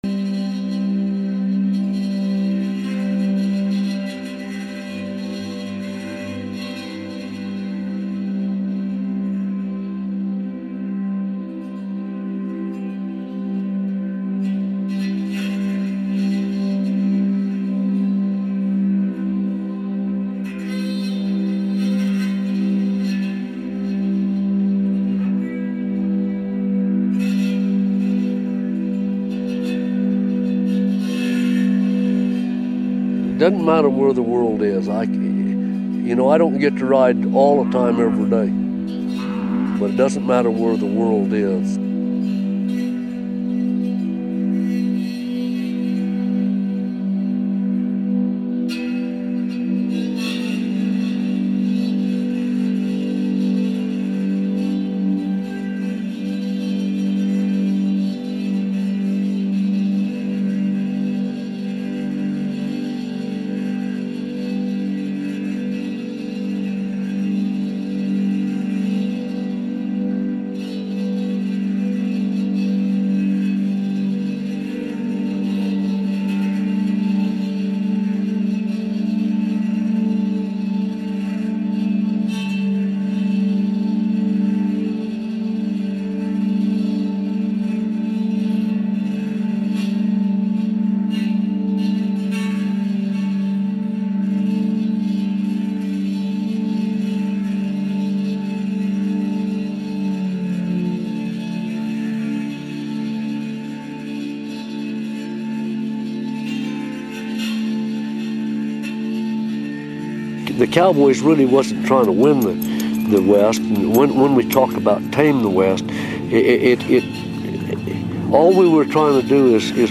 vocals, guitars, accordion and harmonium
vocals, guitars, banjo and resonator guitar